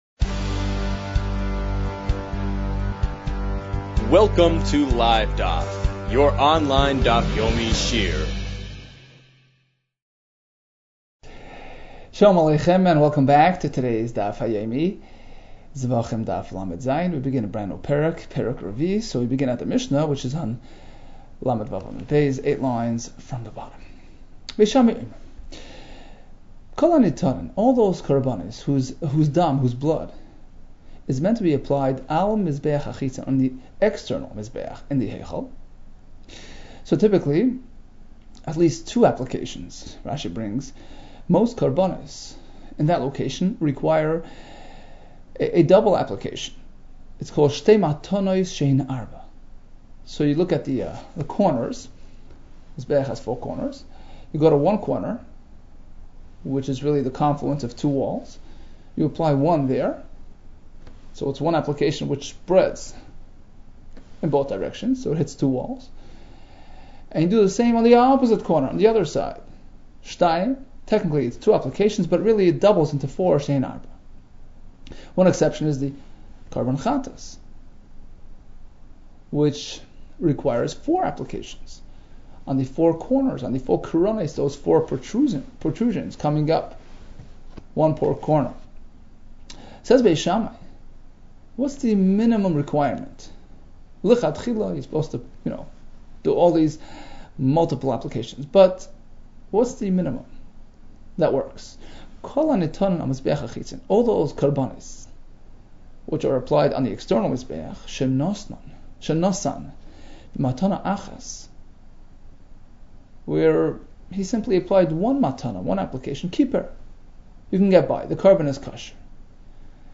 Zevachim 37 - זבחים לז | Daf Yomi Online Shiur | Livedaf